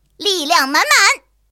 野牛补给语音.OGG